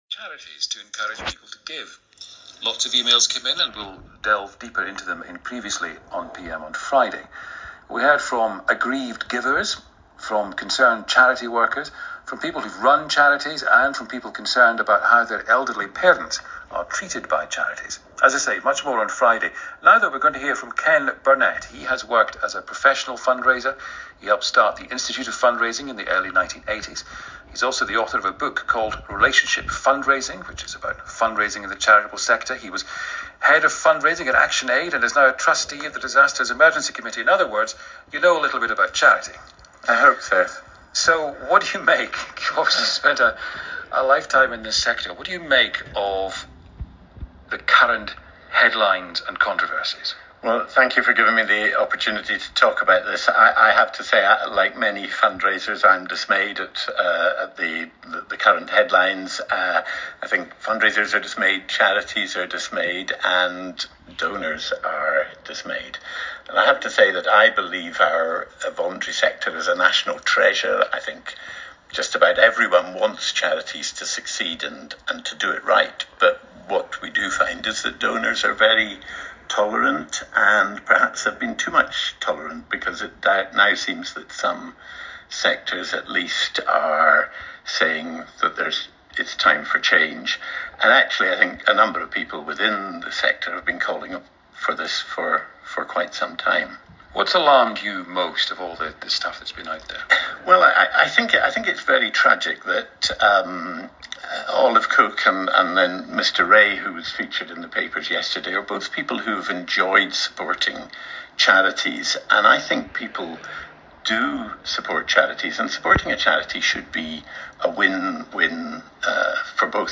The PM programme on BBC Radio 4 – one of the BBC’s flagship news programmes – wanted to interview me that afternoon, at 5.00pm. They were looking for someone to respond to the latest round of shock horror critical press commentaries supposedly exposing the shadier side of how fundraisers ply their trade.
After a nerve-testing 30-minute wait in the Green Room (which isn’t green and really is more of a corridor) I was soundlessly ushered into the darkened studio of the renowned Eddie Mair, programme host and top-notch interviewer.